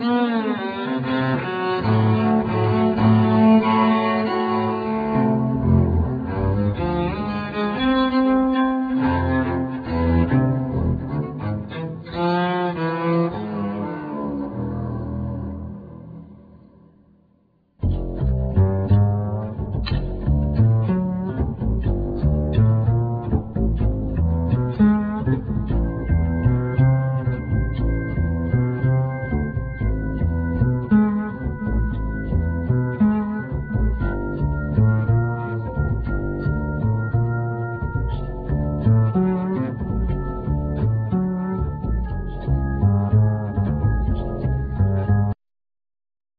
Bass,Piano
Hammond Organ
Electric Bass
Oud
Percussion,Vocals